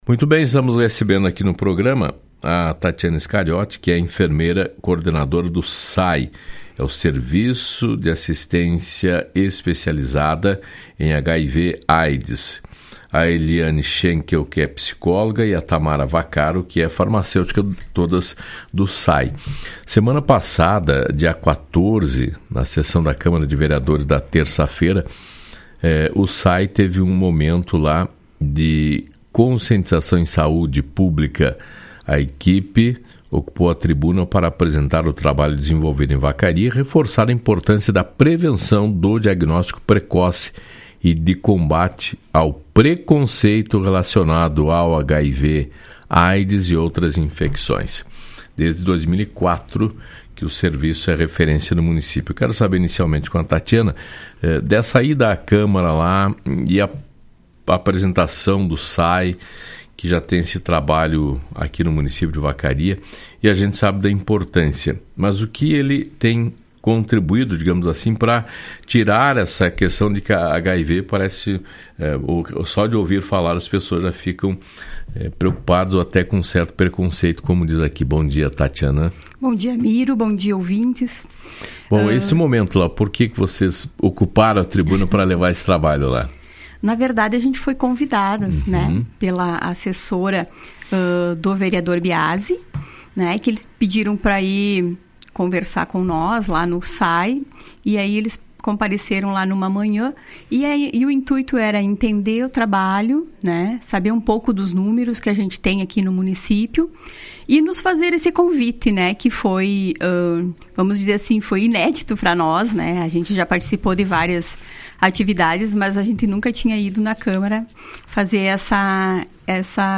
As profissionais do Serviço de Assistência Especializada (SAE) de Vacaria concederam uma entrevista ao programa Fala Cidade, da Rádio Esmeralda, nesta segunda